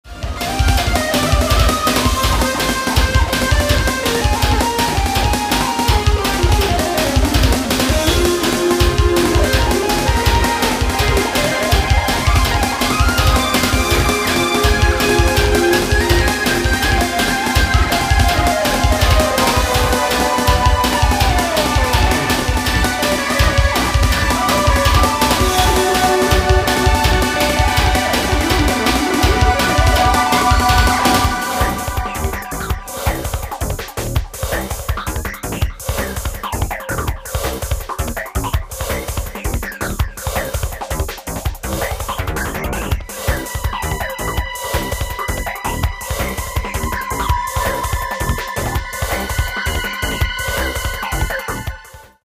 this CD features very lush soundscapes, rich textures
million-note instrumental madness